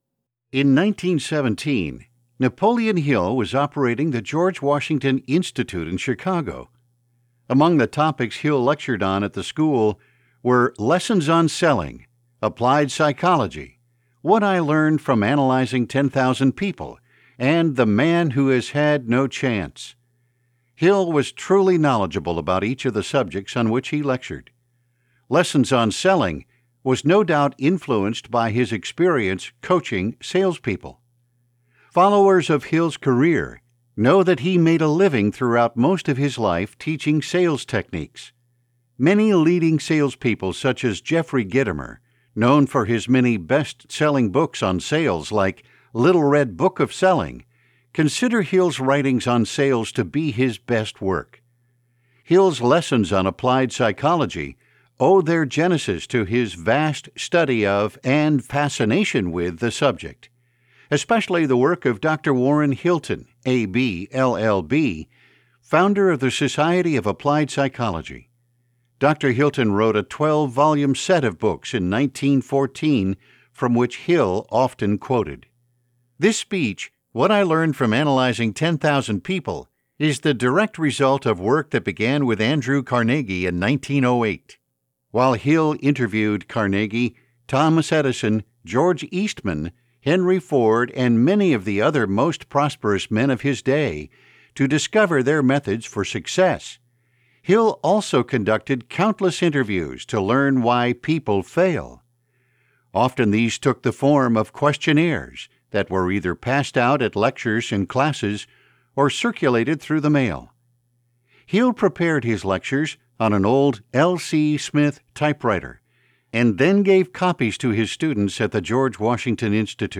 Genre: Audiobook.